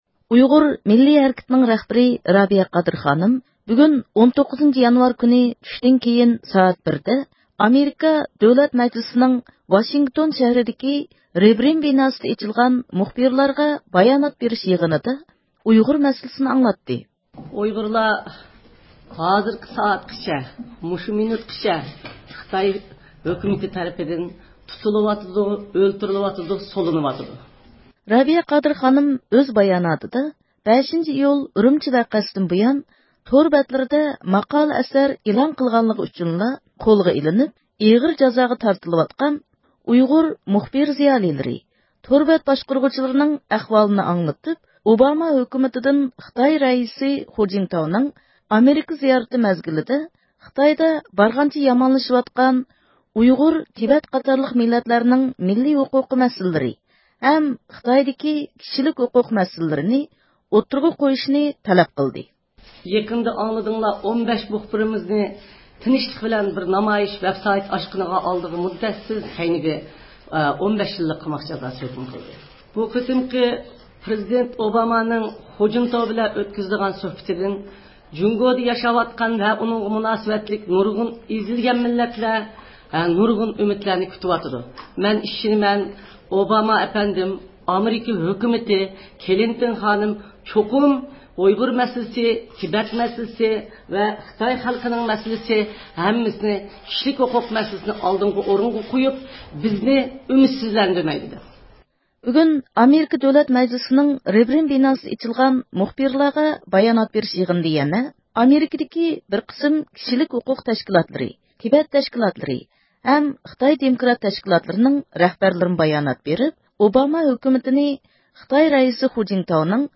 ئۇيغۇر مىللىي ھەرىكىتىنىڭ رەھبىرى رابىيە قادىر خانىم، بۈگۈن 19-يانۋار چۈشتىن كېيىن سائەت 1:00 دە، ئامېرىكا دۆلەت مەجلىسىنىڭ ۋاشىنگتون شەھىرىدىكى رېبرېېن بىناسىدا ئېچىلغان مۇخبىرلارغا بايانات بېرىش يىغىنىدا ئۇيغۇر مەسىلىسىنى ئاڭلاتتى.